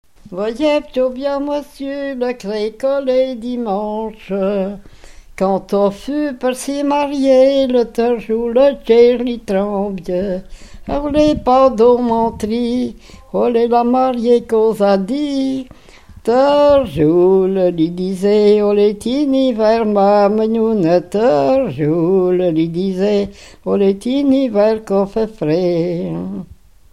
Genre laisse
Enquête Arexcpo en Vendée-Pays Sud-Vendée
Pièce musicale inédite